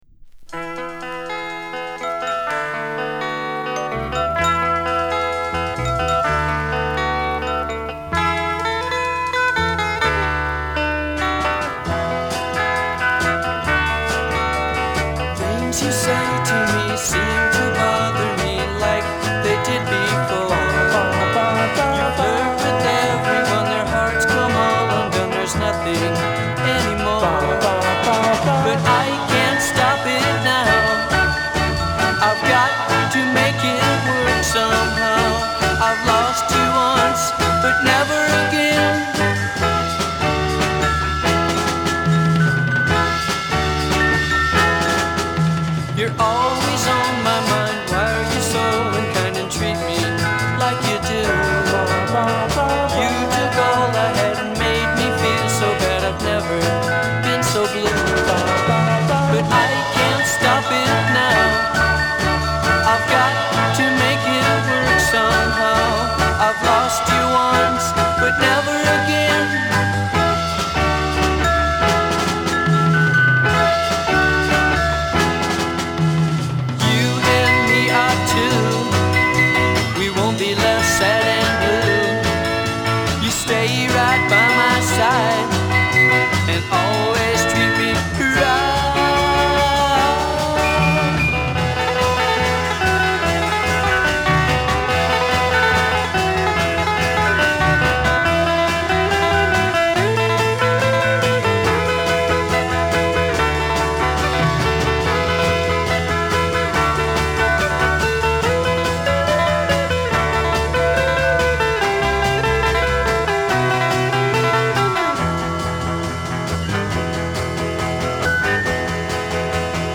B面はメロディアスゆえに繊細な印象を受けるが、雑さが却って際立つ結果となり独特の風情を醸している。